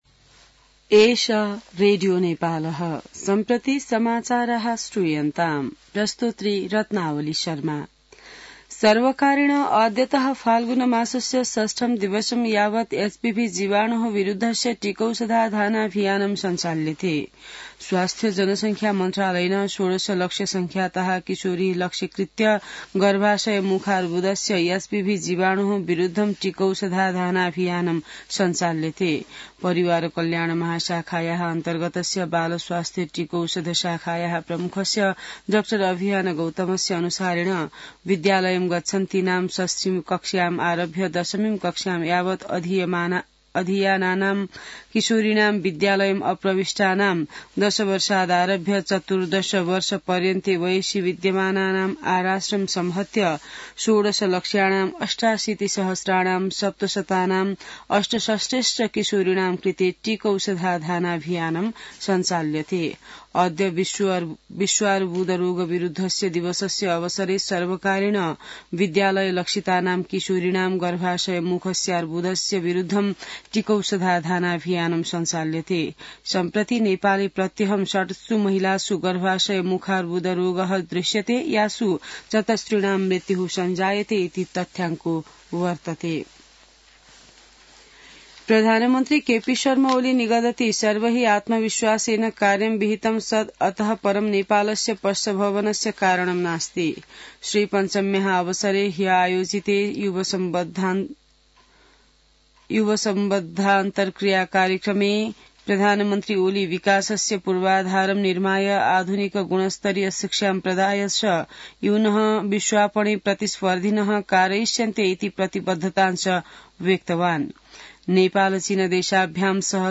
संस्कृत समाचार : २३ माघ , २०८१